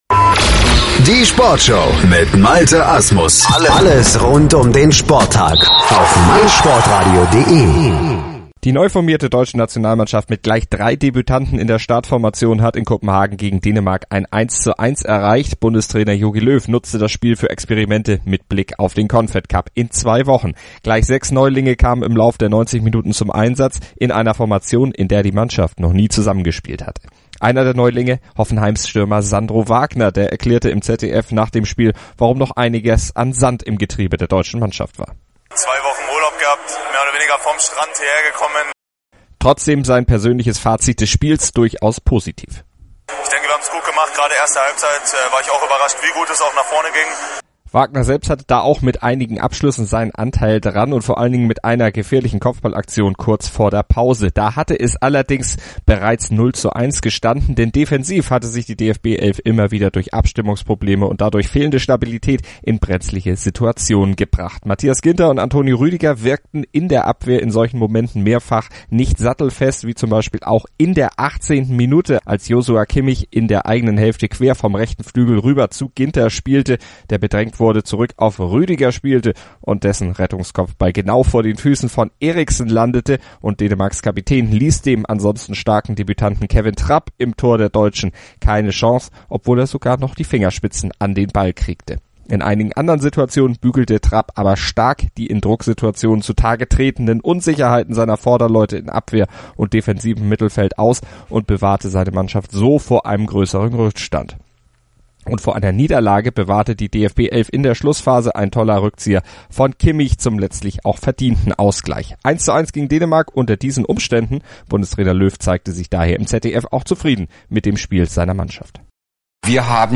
lässt Sandro Wagner und Jogi Löw zu Wort kommen